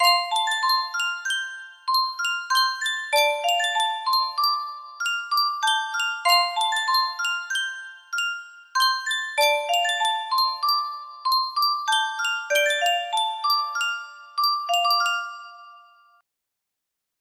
Yunsheng Music Box - Unknown Tune 4 5199 music box melody
Full range 60